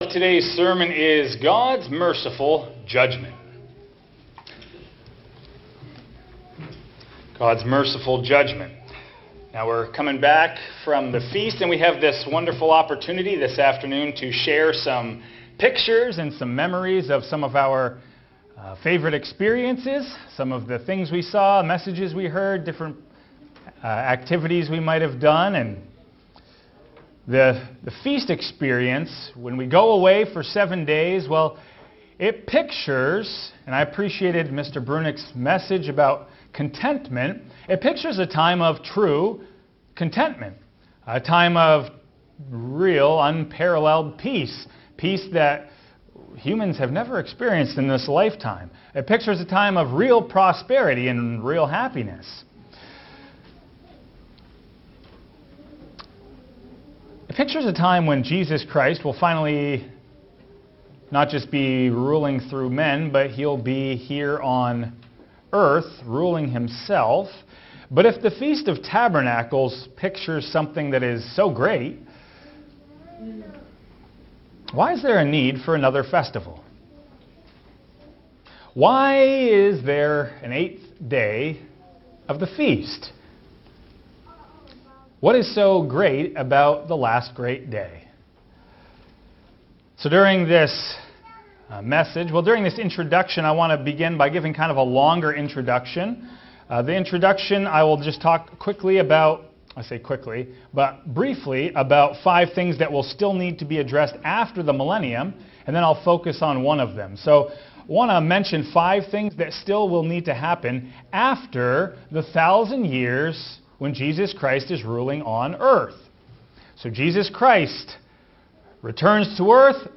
This sermon explores the profound balance between God's justice and mercy in His plan for humanity. After Christ’s 1,000-year reign with the saints, a second resurrection will bring all who have ever lived to stand before the judgment seat of God, fulfilling the promise that every knee will bow and every tongue will confess.